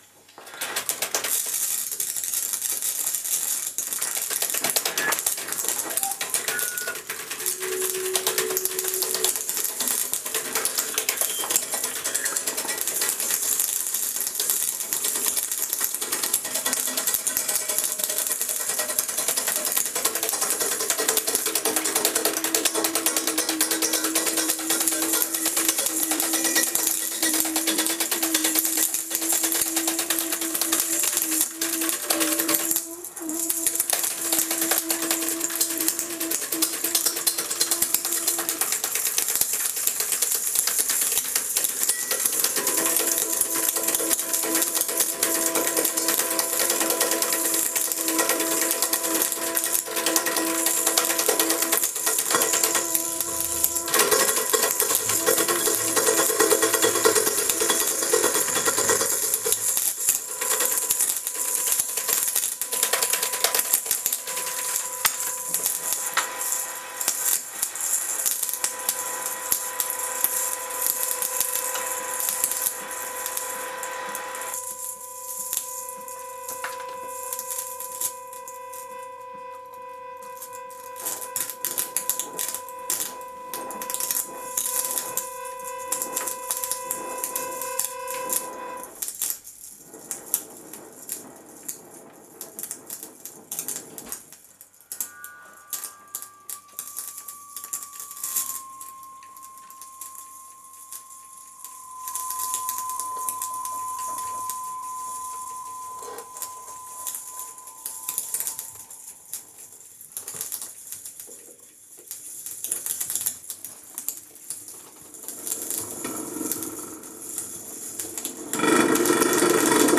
live at 64